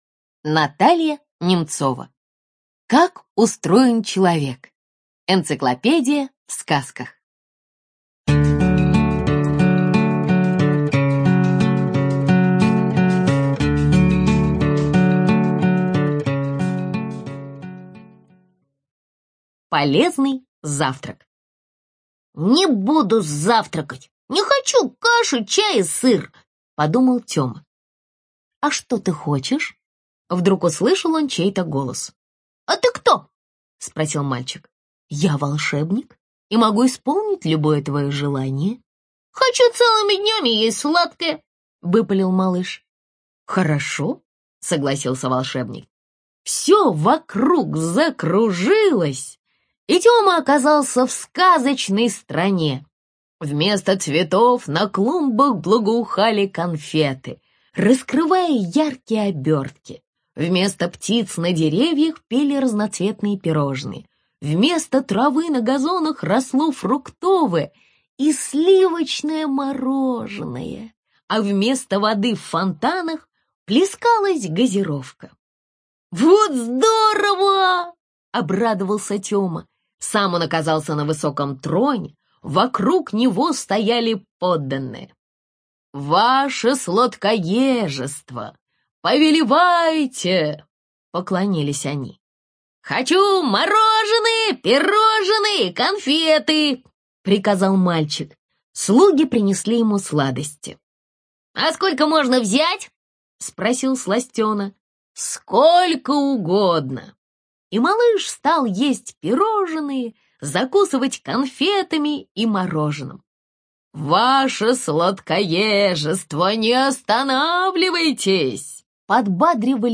ЖанрСказки